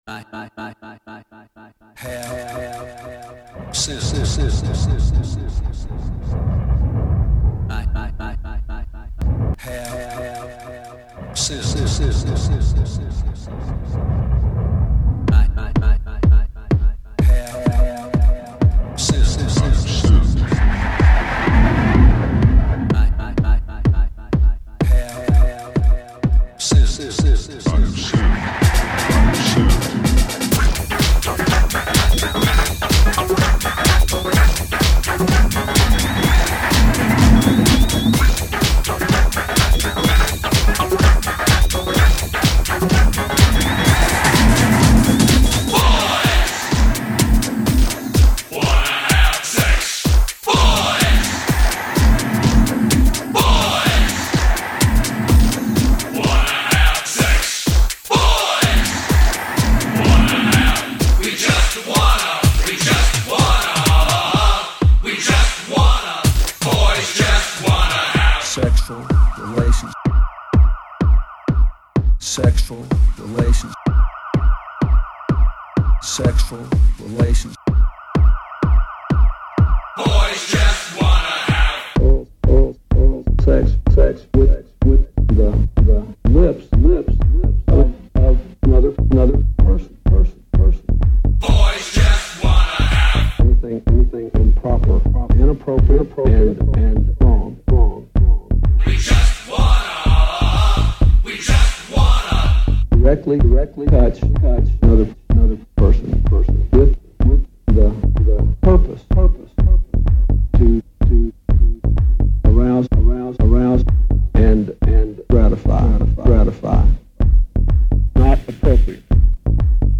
they are mixed to flow together.